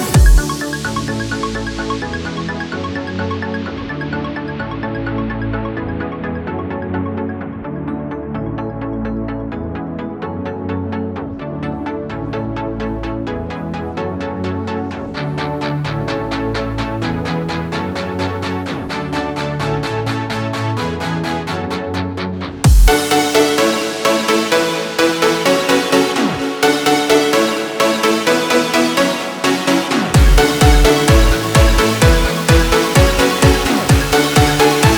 Electronic Dance
Жанр: Танцевальные / Электроника